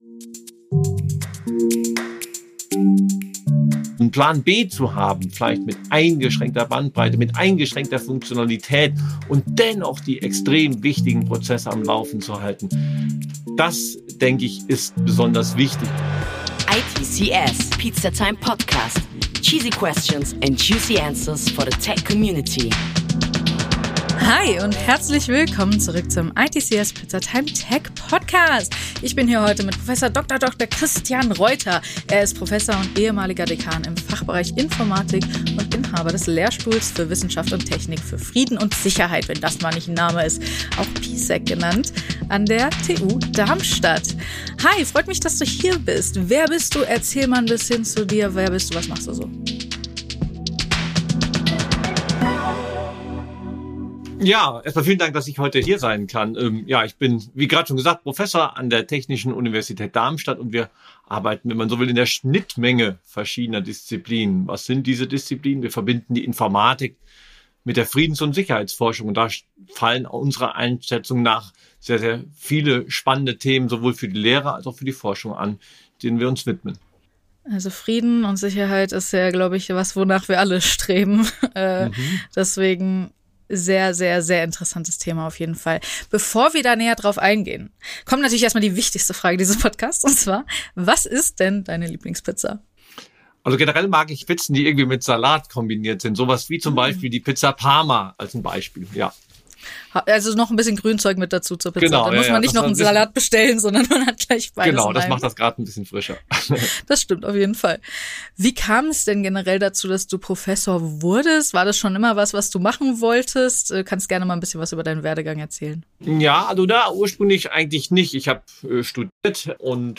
ITCS Pizzatime steht für Snackable Content mit authentischen Einblicken in die Tech- und IT-Branche und das alle 2 Wochen frisch serviert! Seid dabei und lasst Euch inspirieren, wenn wir Brancheninsider aus den verschiedensten Bereichen zu den aktuellsten und spannendsten Tech- und IT-Trends befragen.